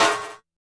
snare05.wav